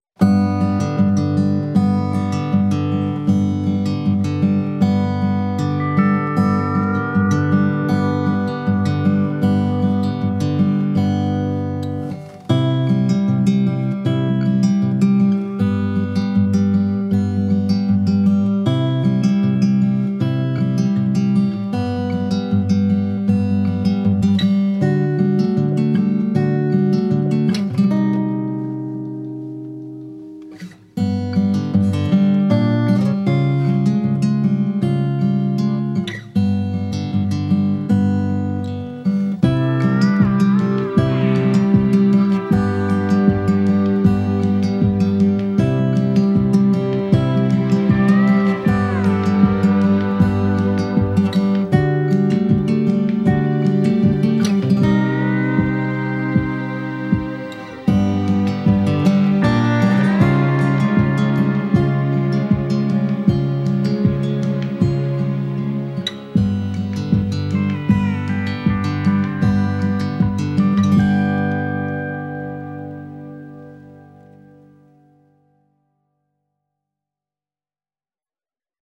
twangy, subtly soaring score